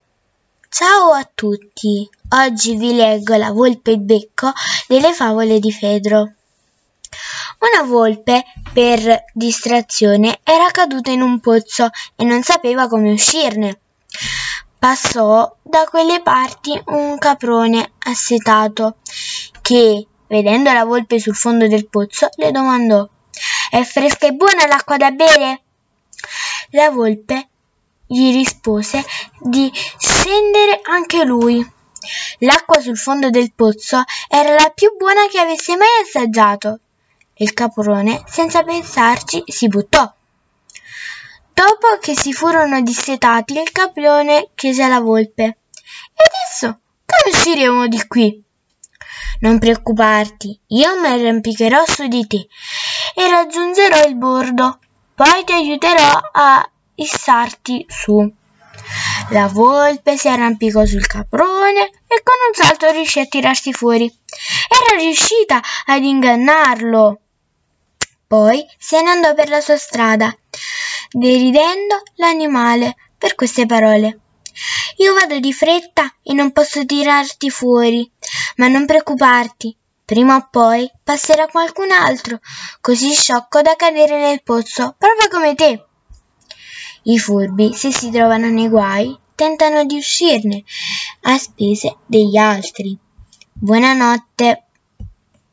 Oggi vi leggo la favola di Fedro “La volpe e il becco”